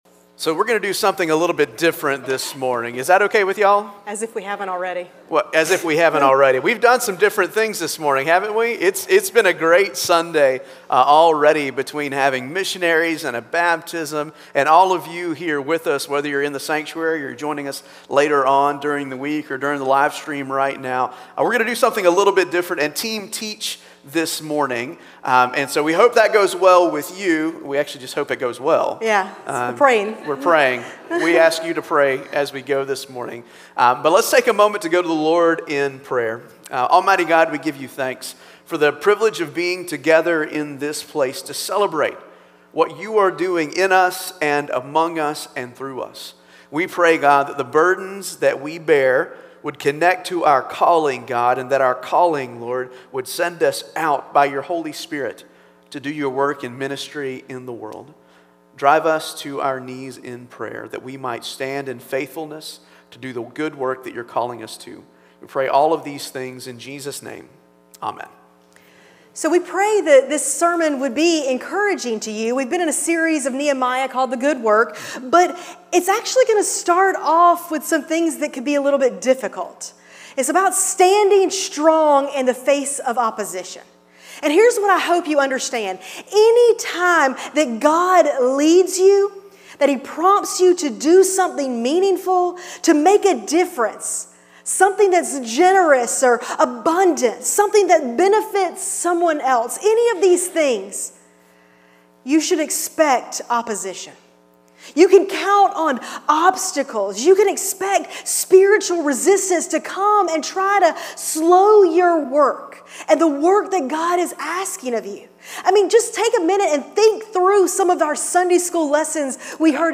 Passage: Nehemiah 4:1-6 Service Type: Traditional Service